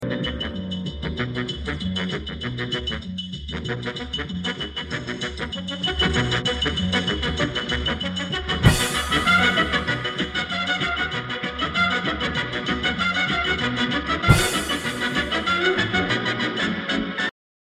Твистовая...